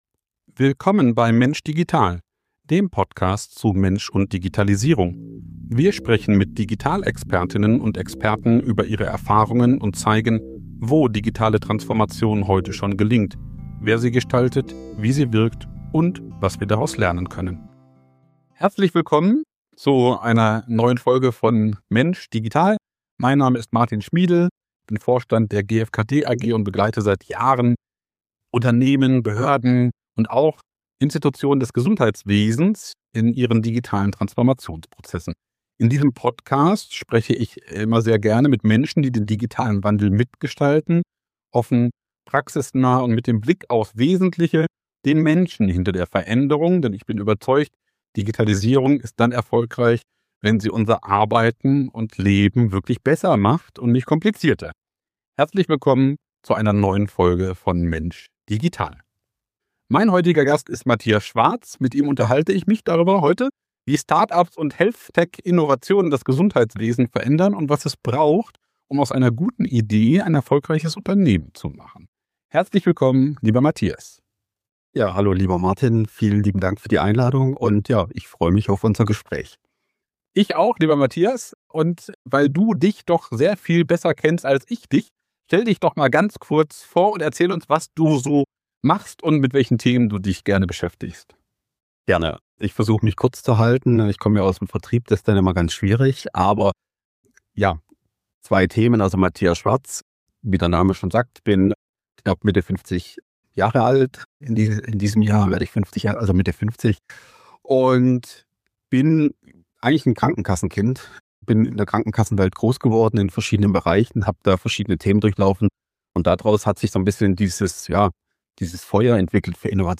Im Fokus stehen der digitale Reifegrad der Branche, der steigende Innovationsdruck durch Fachkräftemangel und Finanzierungslücken sowie die Frage, warum Resilienz, Marktverständnis und Prävention entscheidende Erfolgsfaktoren sind. Ein Gespräch über Wendepunkte, Pflege als Innovationsfeld und warum HealthTech kein Sprint, sondern ein Marathon ist.